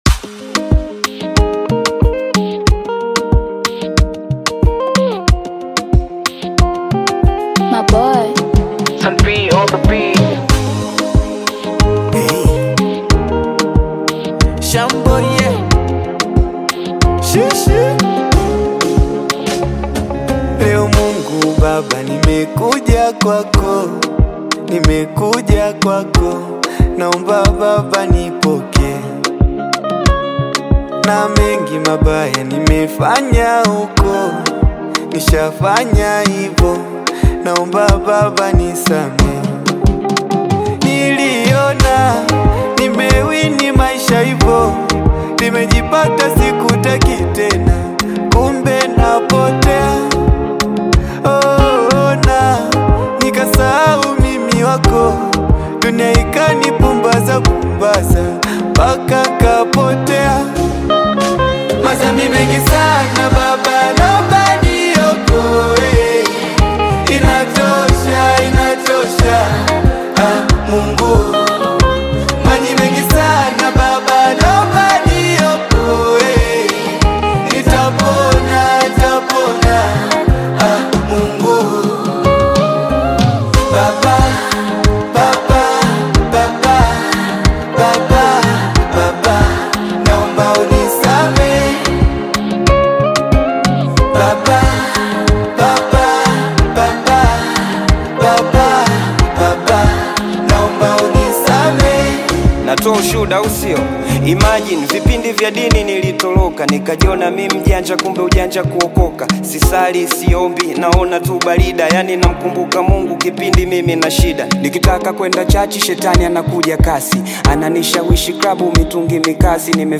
emotional track
adds expressive verses
With its sincere lyrics and soulful production